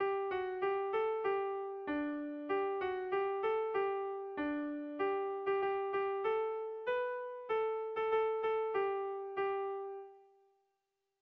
Dantzakoa
AABD